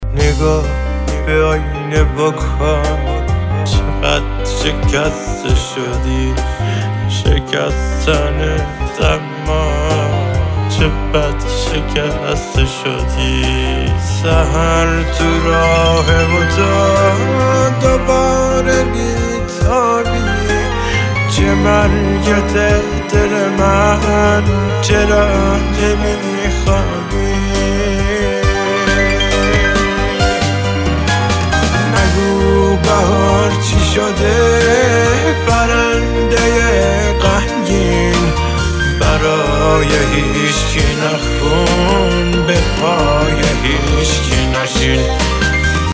آهنگ موبایل احساسی